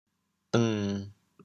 “当”字用潮州话怎么说？
deng1.mp3